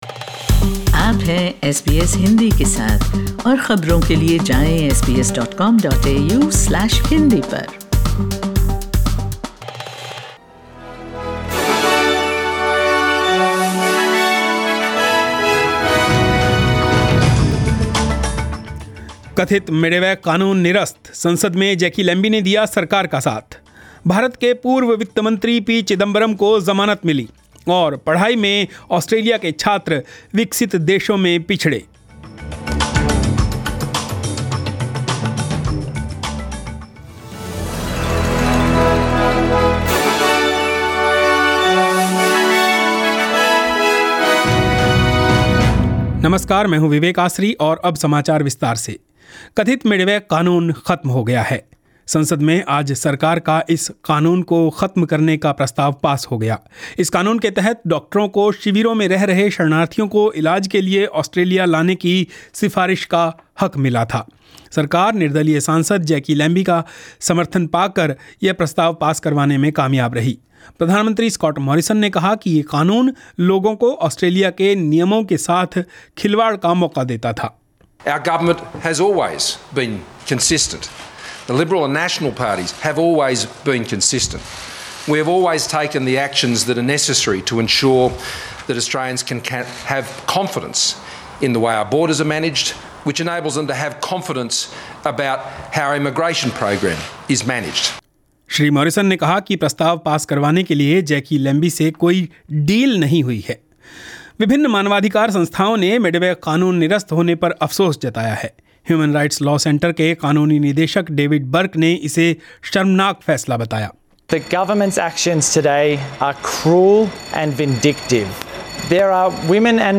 News in Hindi